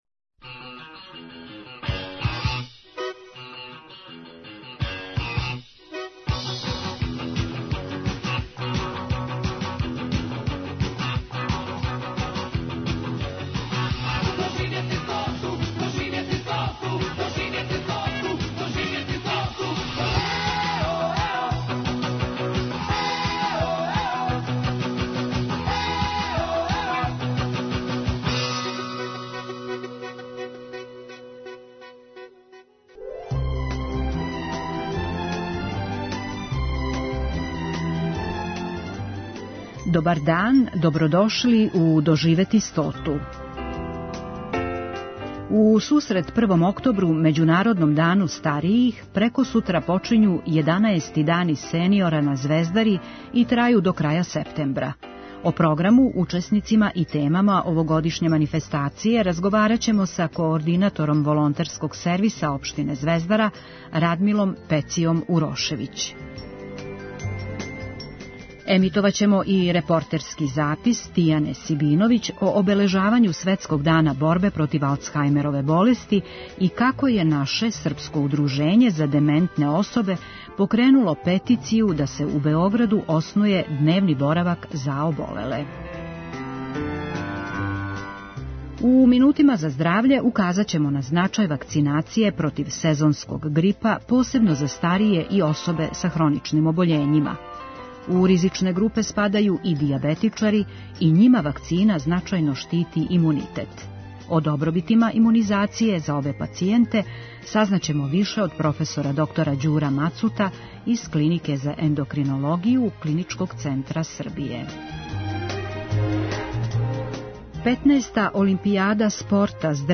Емитоваћемо и репортерски запис